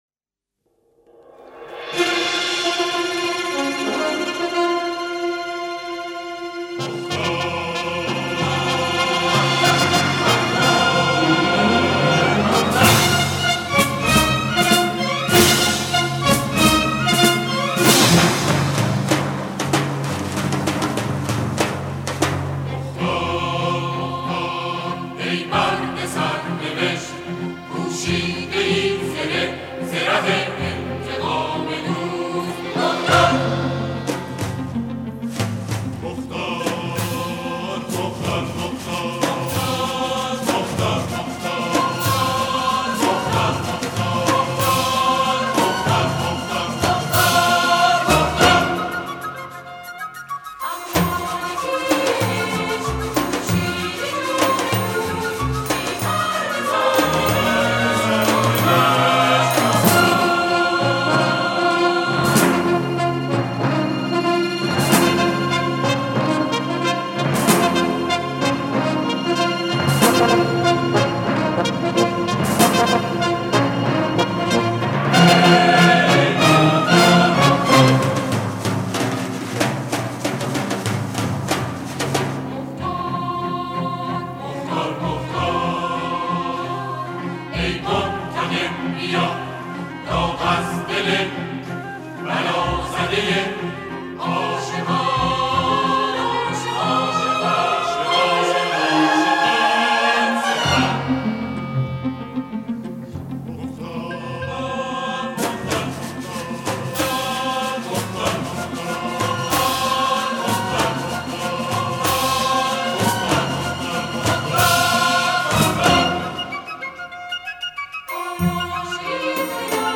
آلبوم مبتنی بر موسیقی ارکسترال و کرال است